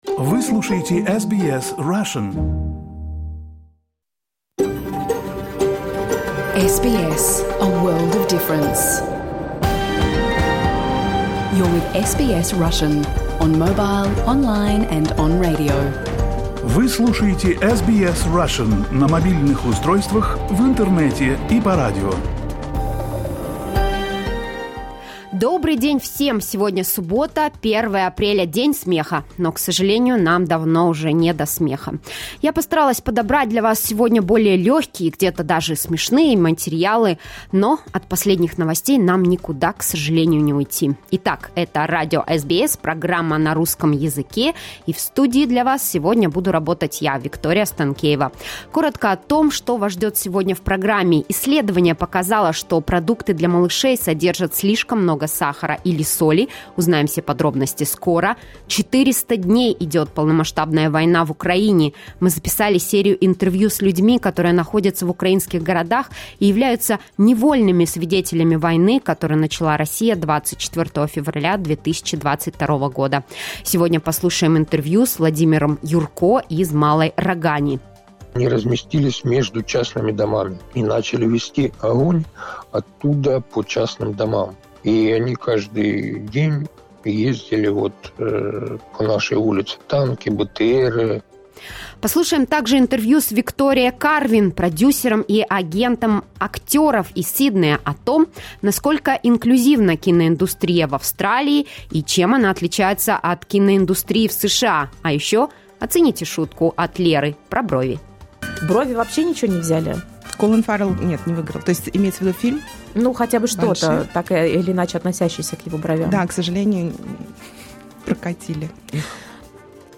You can listen to SBS Russian program live on the radio, on our website and on the SBS Radio app.